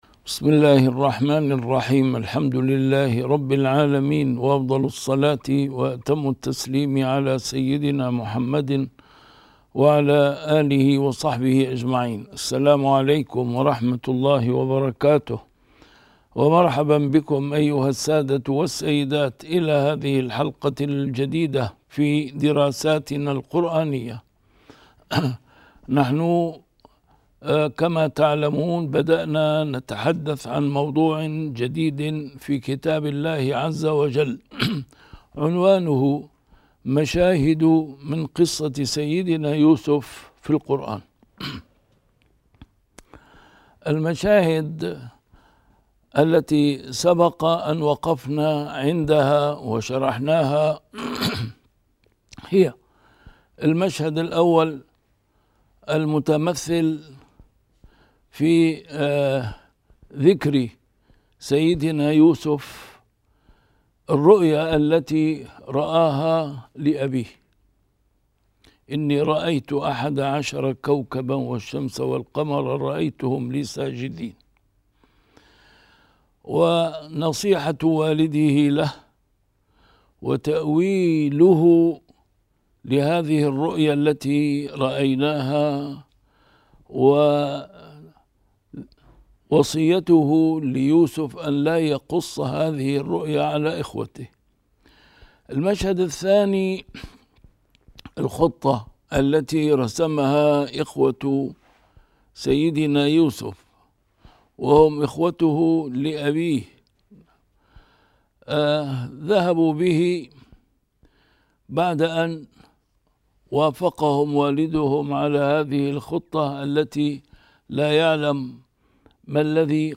A MARTYR SCHOLAR: IMAM MUHAMMAD SAEED RAMADAN AL-BOUTI - الدروس العلمية - مشاهد من قصة سيدنا يوسف في القرآن الكريم - 3 - سيدنا يوسف في بيت عزيز مصر